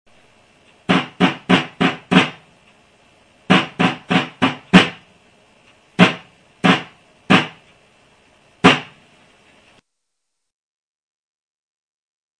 Trommelsignale
(Drummer & Fifer)
Wichtige Trommelsignale und ein paar Rhythmen -